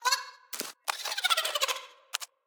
Sfx_creature_trivalve_wave_01.ogg